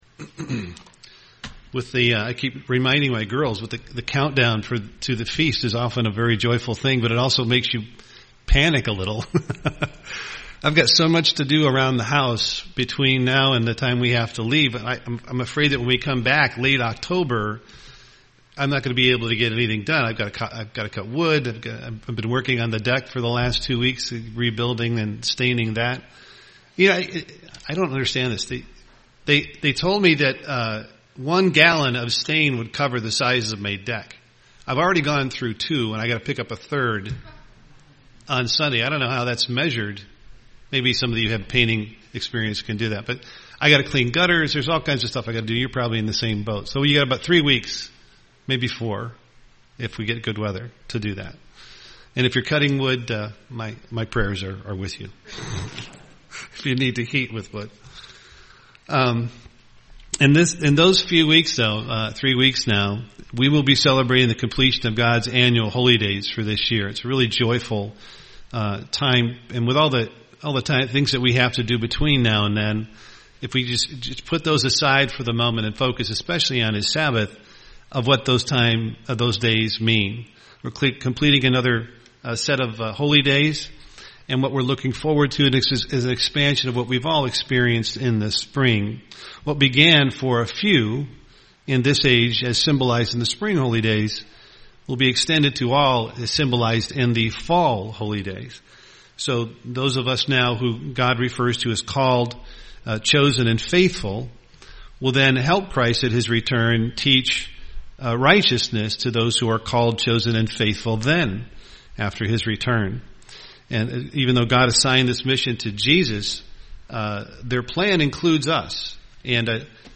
UCG Sermon peacemakers Studying the bible?